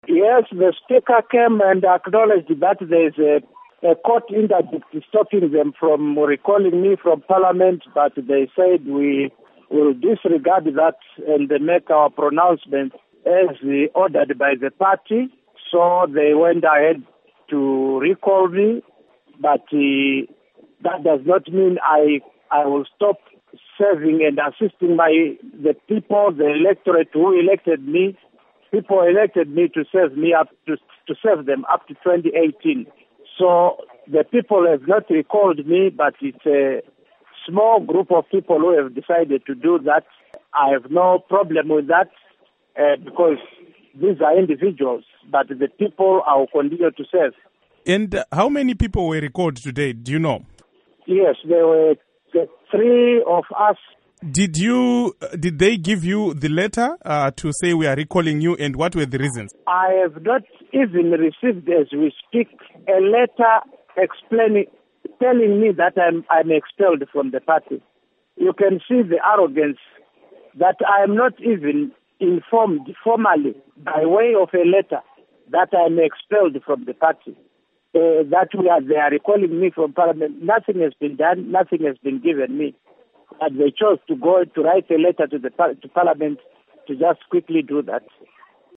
Interview With Kudakwashe Bhasikiti on Zanu PF Recall